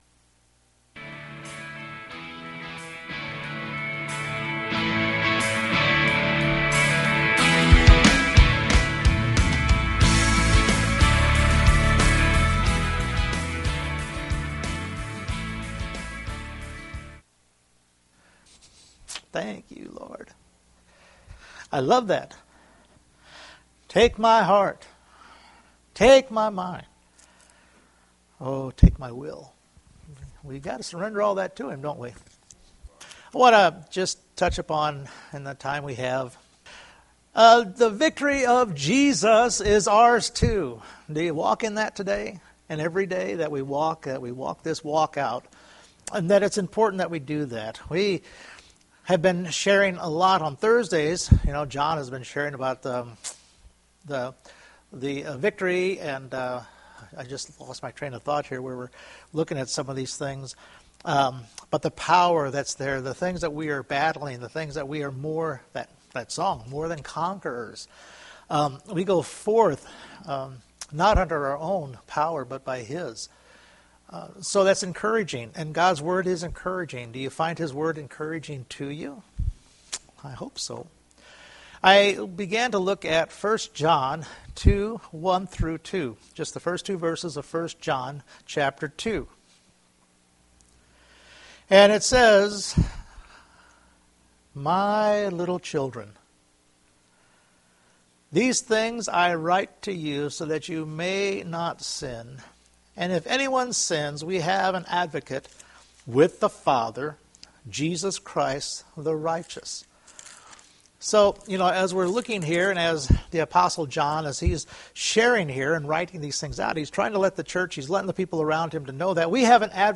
1 John 2:1-2 Service Type: Sunday Morning Jesus is the propitiation for our sins and for the whole world!